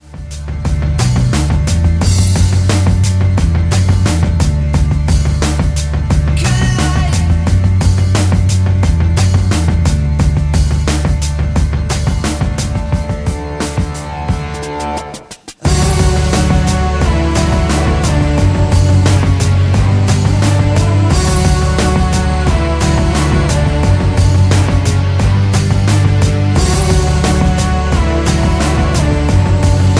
D
Backing track Karaoke
Rock, 2000s